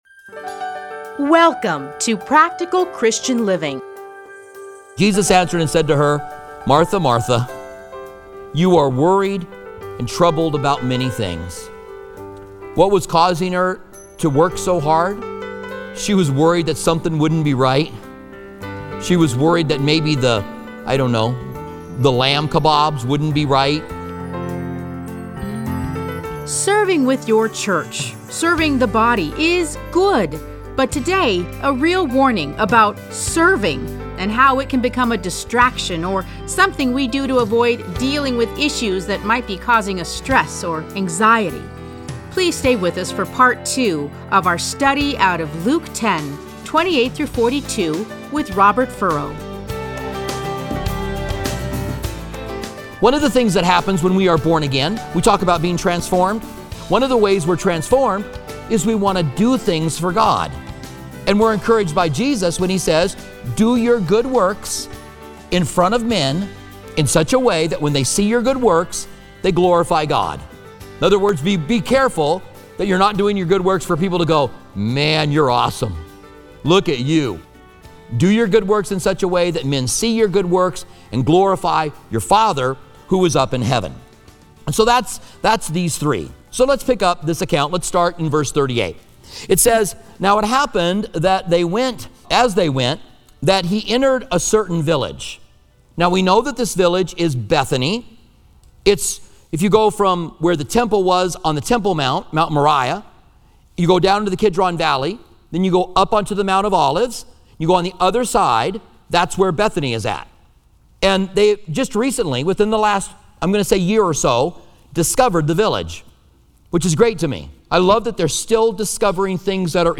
Listen to a teaching from Luke Luke 10:38-42 Playlists A Study in Luke Download Audio